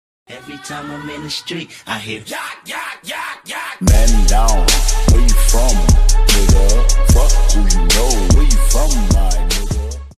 dunyann-en-guzel-10-saniyelik-muzigi-bagimlilik-yapiyor_lJXF1GO.mp3